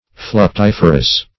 Search Result for " fluctiferous" : The Collaborative International Dictionary of English v.0.48: Fluctiferous \Fluc*tif"er*ous\, a. [L. fluctus wave + -ferous.] Tending to produce waves.